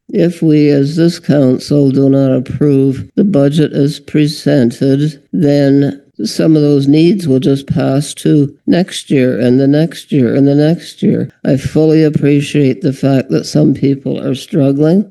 Mayor Judy Brown warned against pushing expenses to future councils.